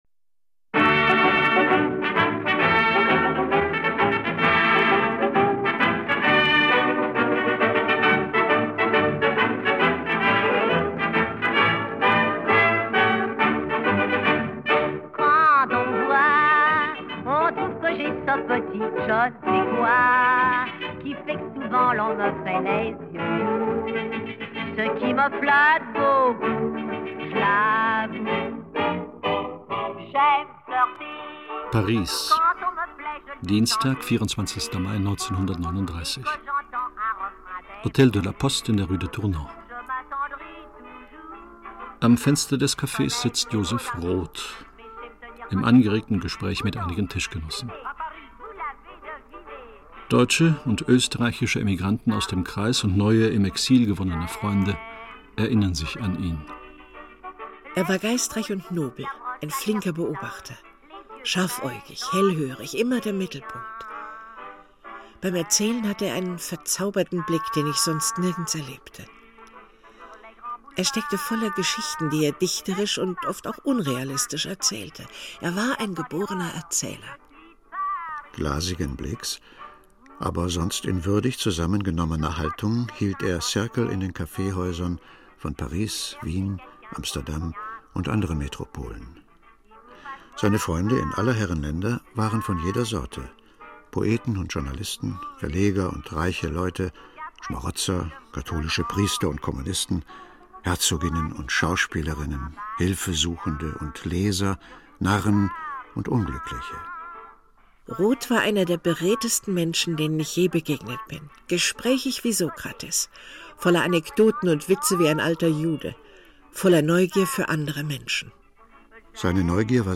Audio des Hörstücks